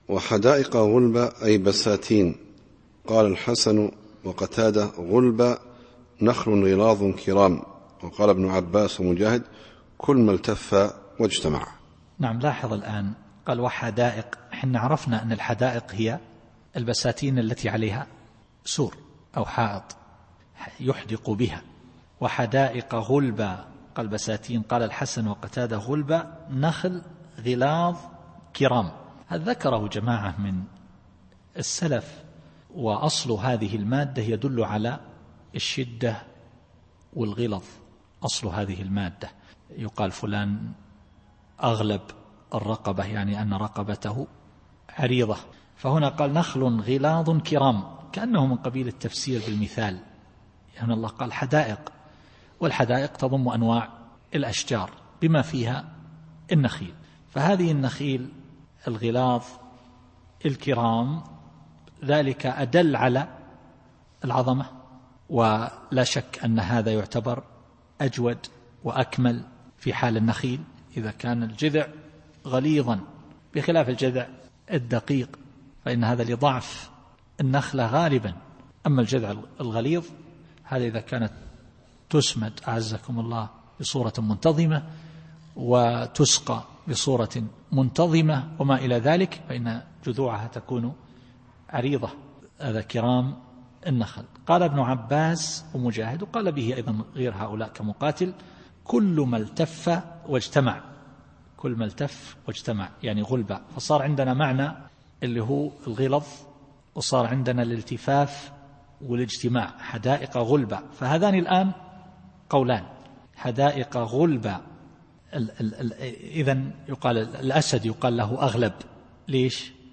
التفسير الصوتي [عبس / 30]